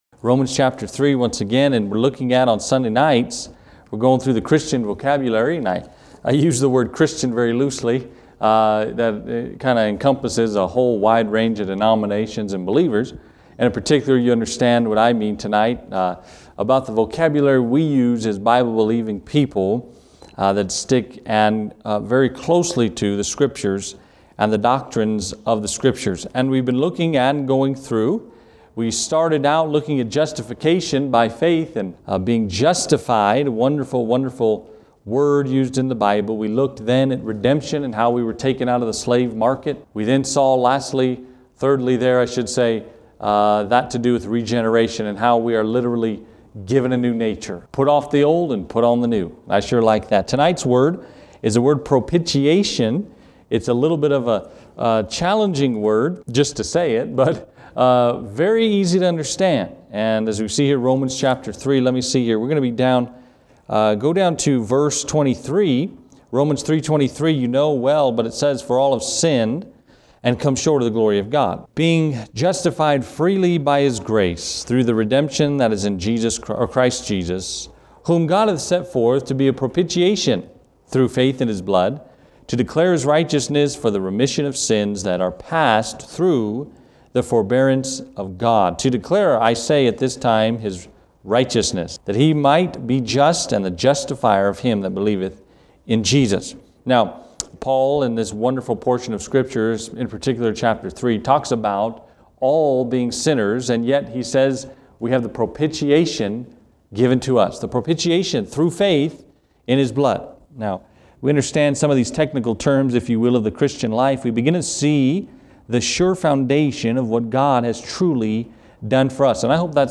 Romans 3:23-31 discusses this, but what does it mean? Listen to the explanation in this sermon!